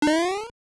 Sound effect of "Big Mario Jump" in Super Mario Bros. Deluxe.
SMBDX_Big_Jump.oga